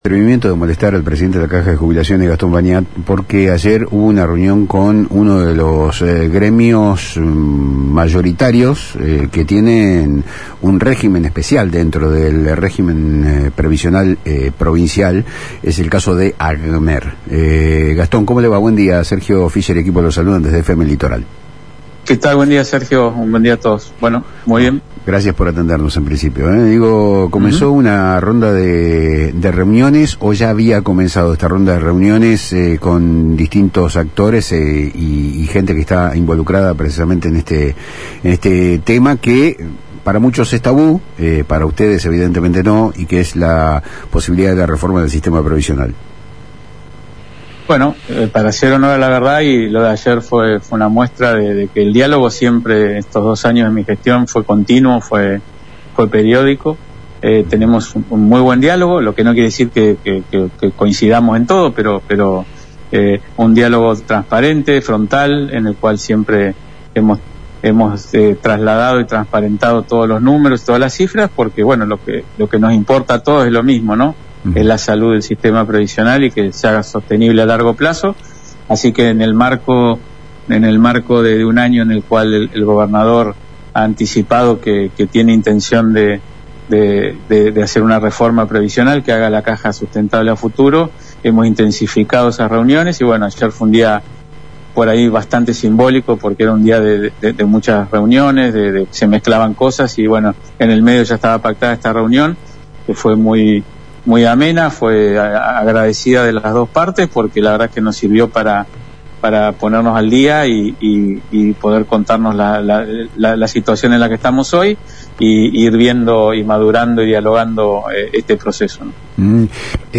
En diálogo con FM Litoral, el funcionario reveló que el impacto real de los cambios no será inmediato, sino que busca la sostenibilidad del sistema en el mediano y largo plazo.